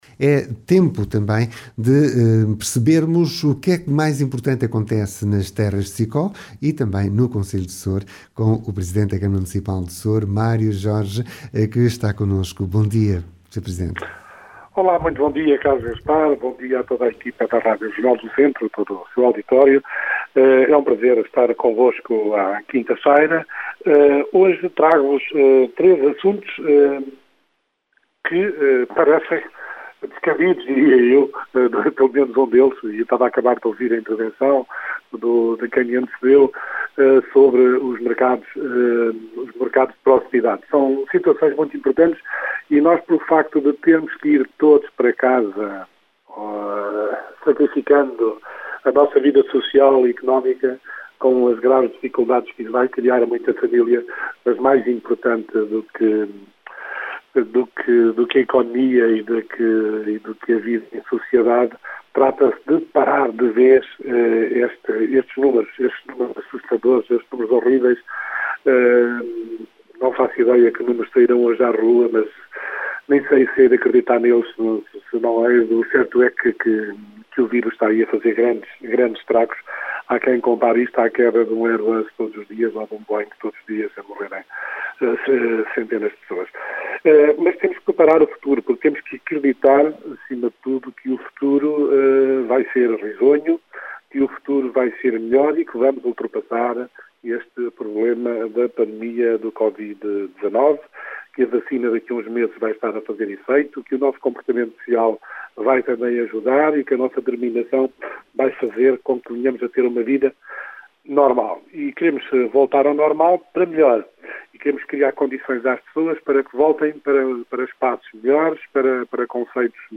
Soure e a Terras de SICÓ têm voz com o presidente da Câmara Municipal de Soure, Mário Jorge Nunes, que hoje destacou os Projectos Terras Sicó e o emparcelamento do vale do mondego.